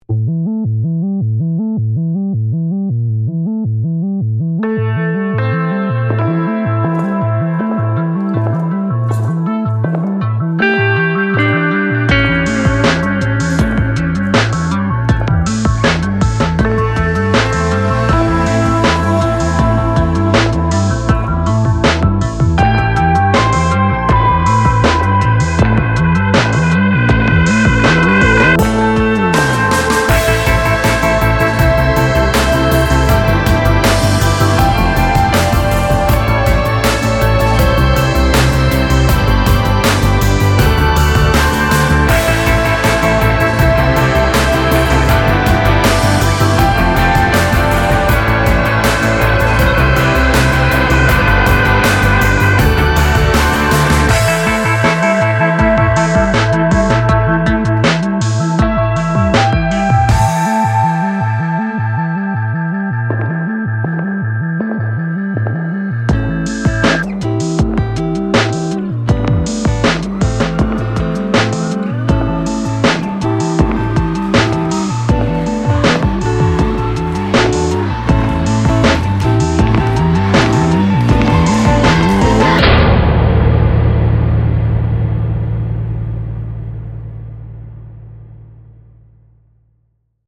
It has riot noises and the cell door slam at the end .